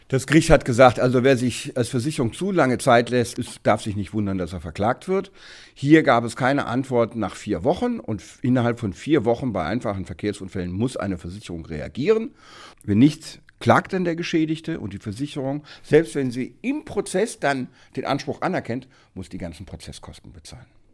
O-Ton: Geduldsprobe nach dem Unfall – Wie lange darf sich die Versicherung Zeit lassen?